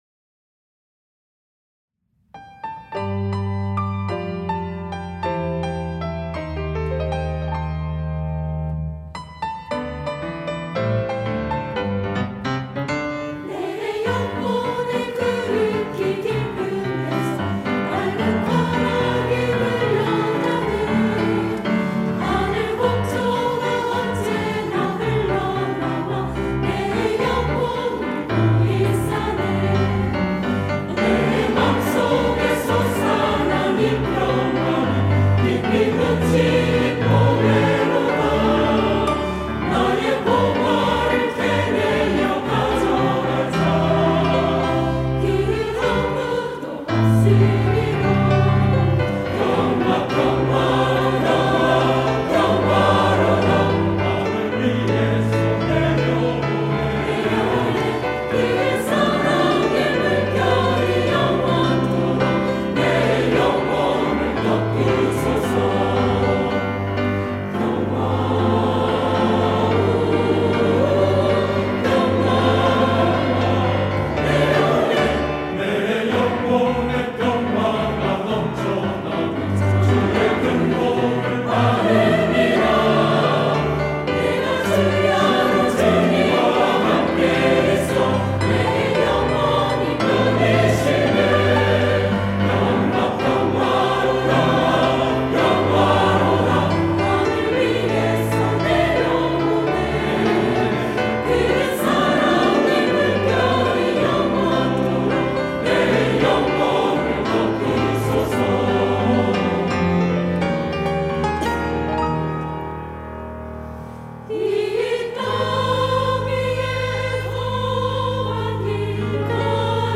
할렐루야(주일2부) - 내 영혼에 그윽히 깊은데서
찬양대